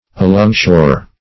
alongshore - definition of alongshore - synonyms, pronunciation, spelling from Free Dictionary Search Result for " alongshore" : The Collaborative International Dictionary of English v.0.48: Alongshore \A*long"shore`\, adv. Along the shore or coast.